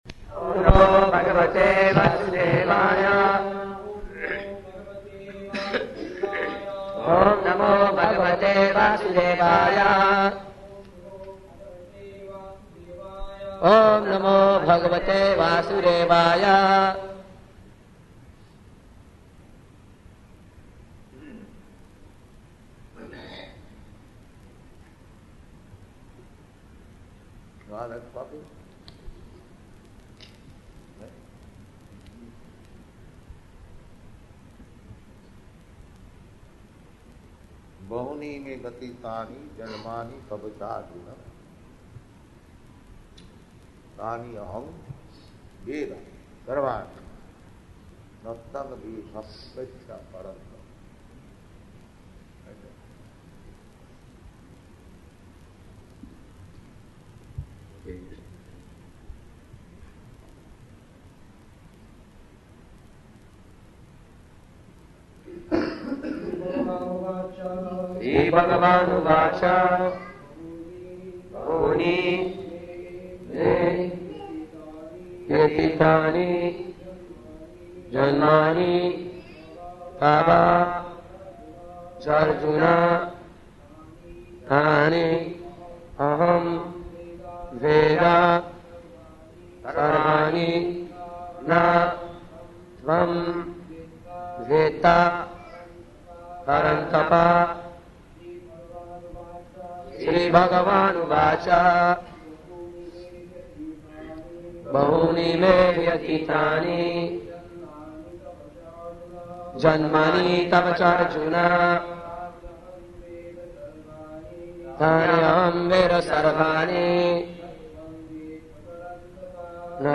February 13th 1974 Location: Vṛndāvana Audio file
[poor recording]
[leads chanting, devotees repeat] Oṁ namo bhagavate vāsudevāya.